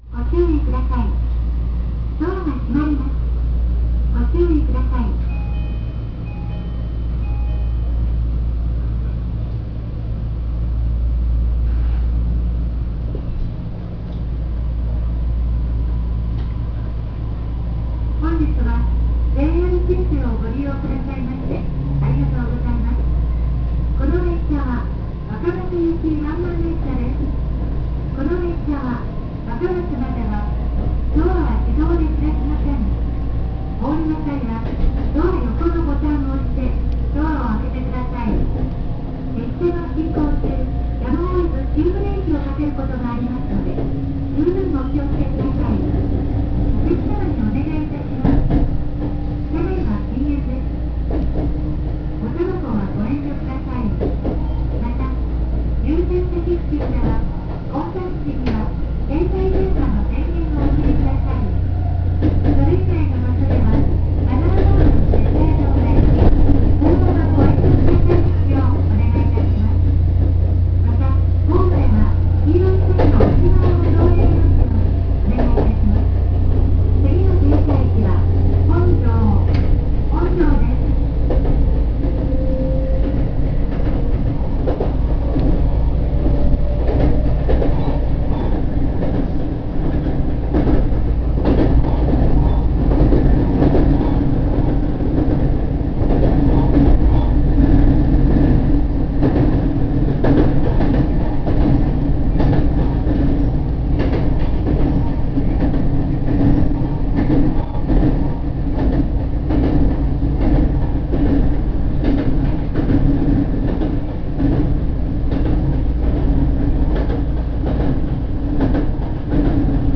〜車両の音〜
・BEC819系走行音
【若松線】折尾→本城（2分51秒：929KB）
ドアチャイムは305系同様、JR東日本汎用タイプになってしまいました。
尚、基本的に半自動ドアですが、ドアが閉まっている場合にも発車前にドアチャイムは流れます。
VVVFは日立製で、取り立てて珍しい音ではありません。速度を出す区間はあるにはあるのですが、単線区間であるがゆえに駅発着時に速度を落とすことが多いため、あまり恵まれた収録環境とは言えません。